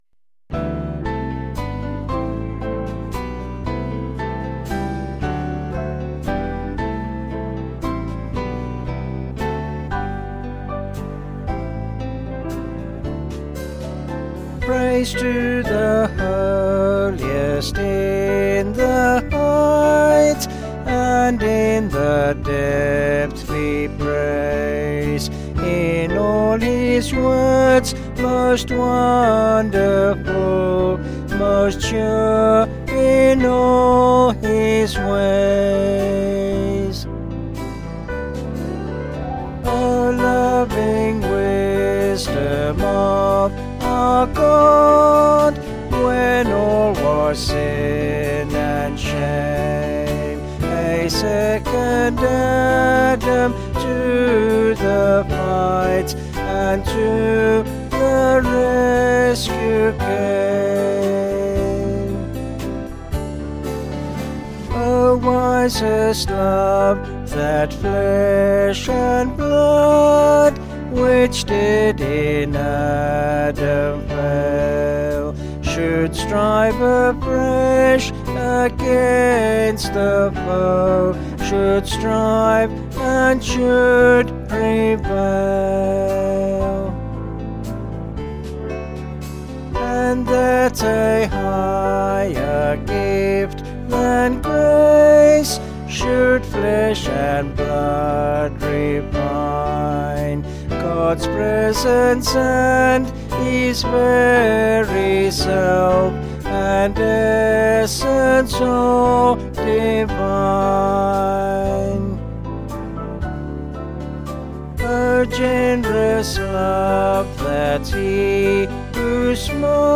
7/G-Ab
Vocals and Band   264.3kb Sung Lyrics